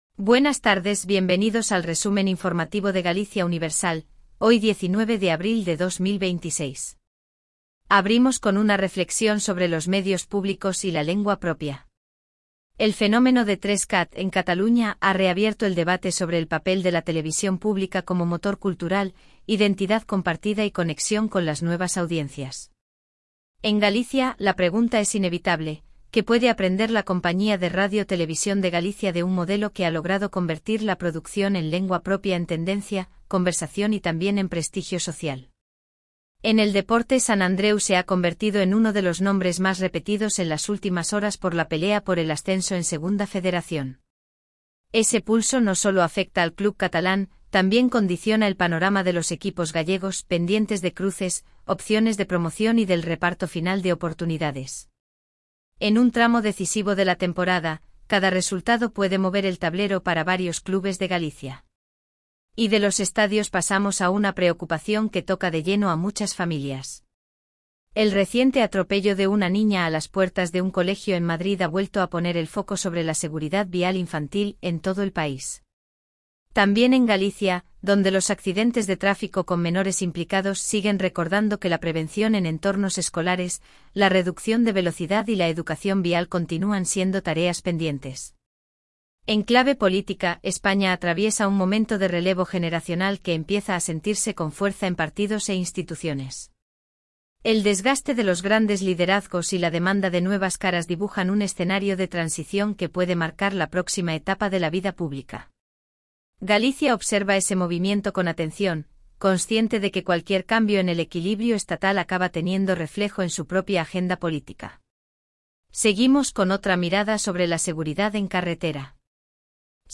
Resumen informativo de Galicia Universal